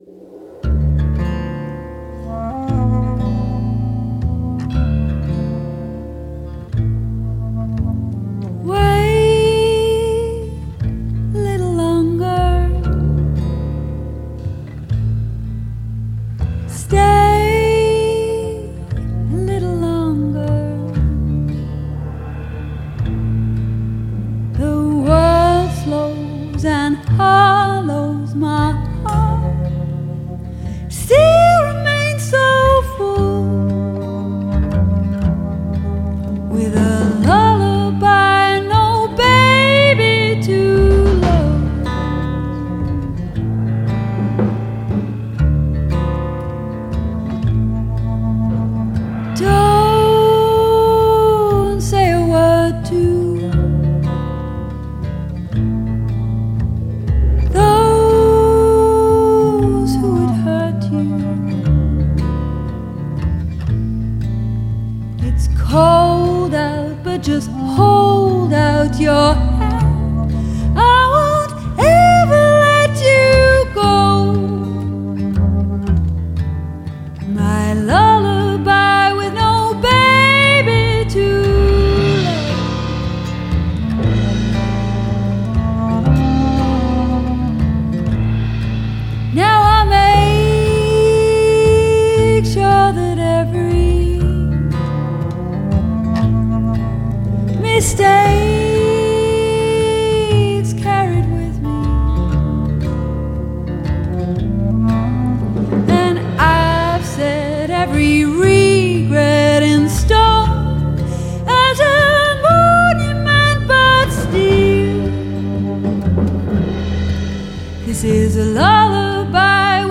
singer-songwiter